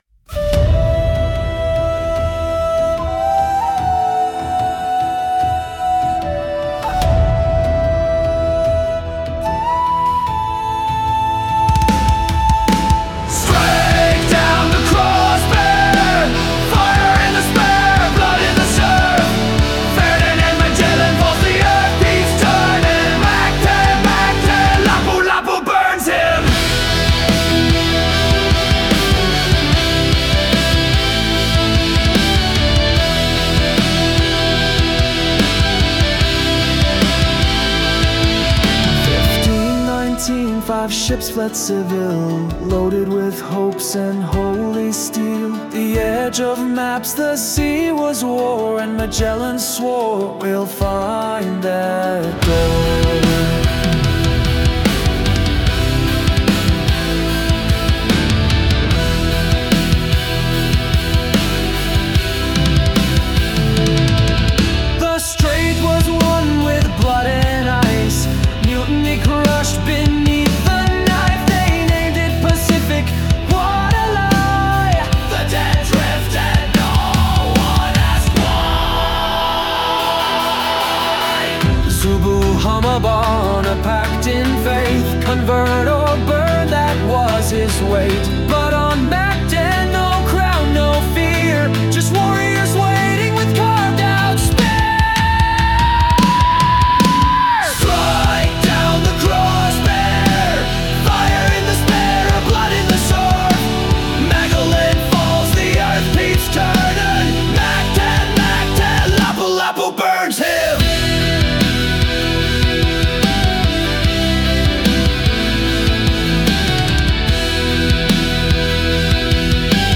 maritime metal odyssey